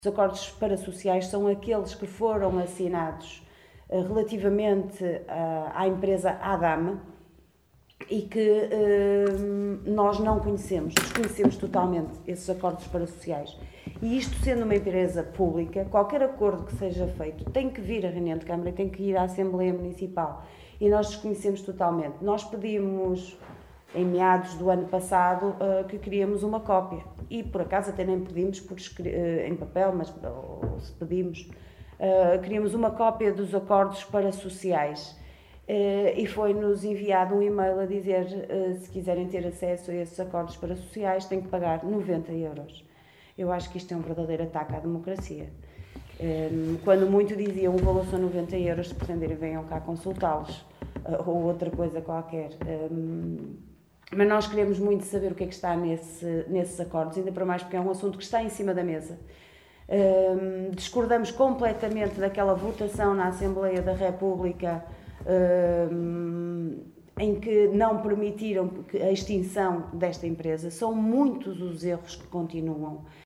Liliana Silva na Conferência de imprensa desta manhã convocada pela OCP.